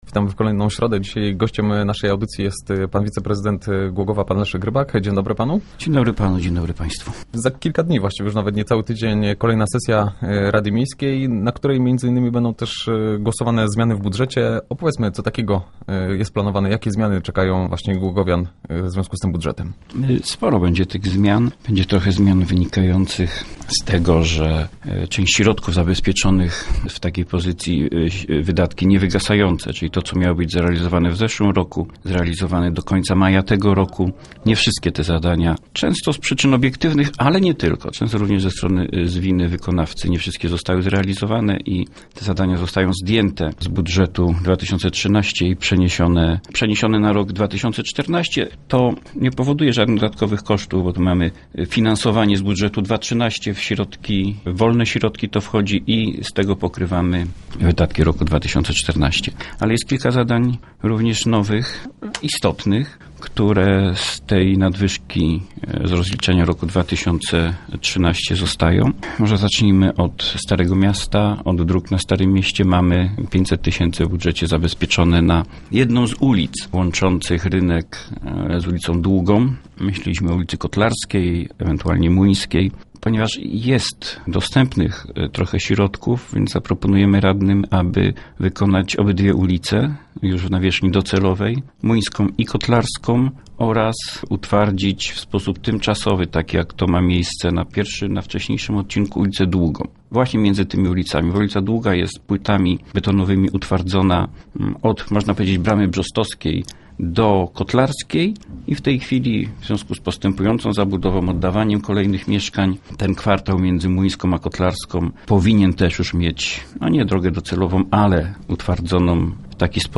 Między innymi o tym rozmawiamy z Leszkiem Rybakiem, zastępca prezydenta Głogowa.